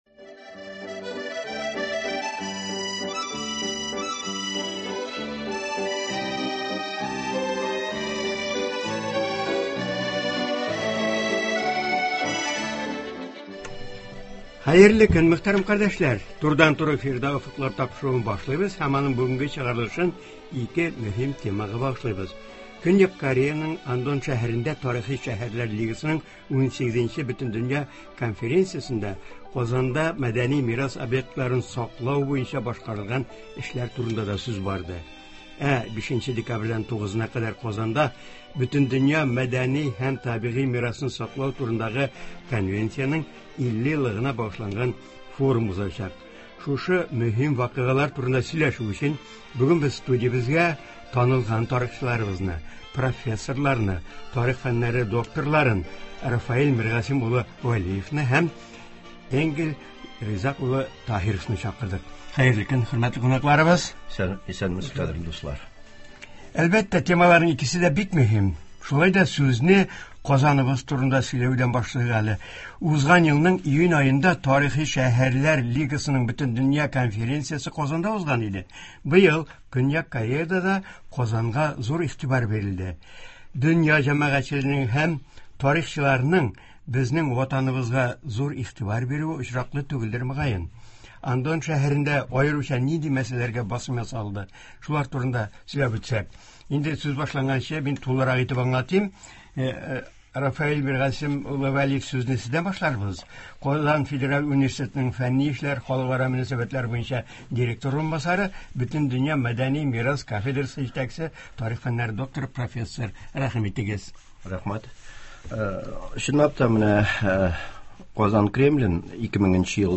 Болар хакында турыдан-туры эфирда